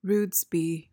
PRONUNCIATION: (ROODZ-bee) MEANING: noun: A rude, boorish person.